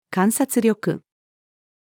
観察力-female.mp3